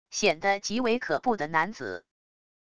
显得极为可怖的男子wav音频